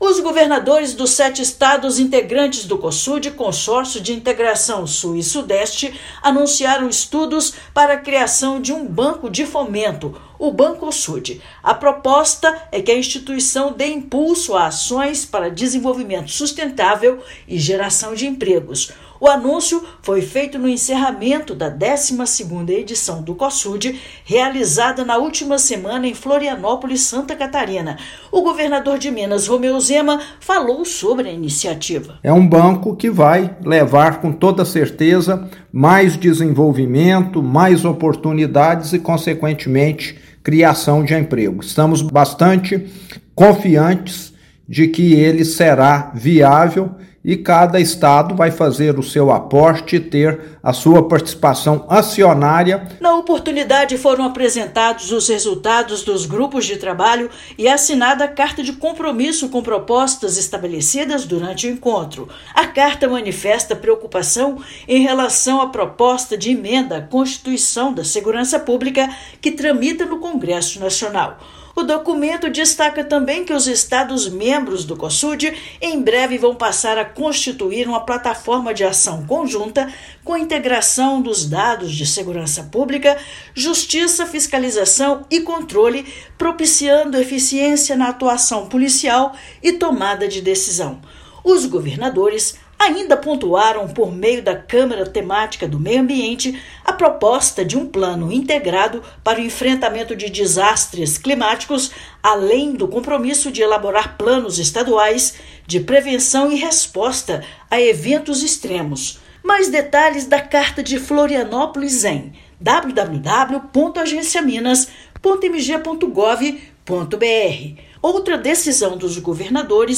Ideia é que a instituição impulsione ações voltadas para o desenvolvimento sustentável e geração de emprego nos estados. Ouça matéria de rádio.